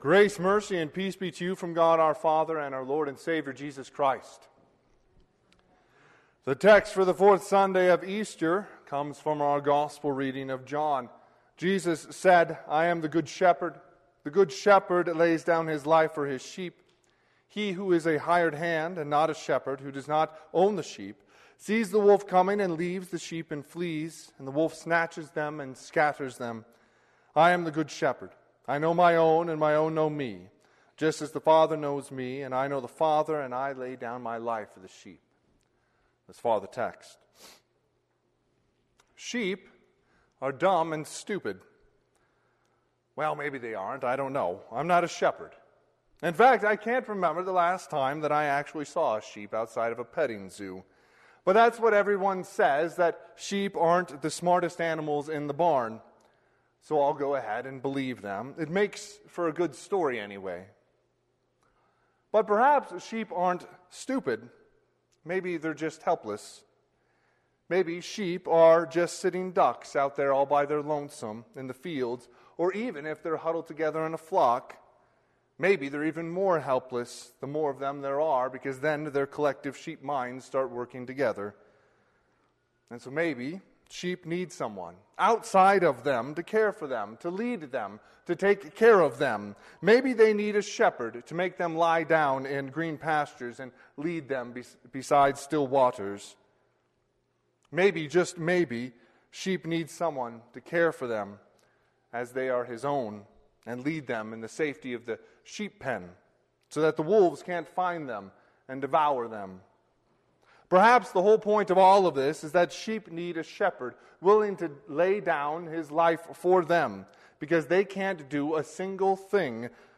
Sermon - 4/25/2021 - Wheat Ridge Lutheran Church, Wheat Ridge, Colorado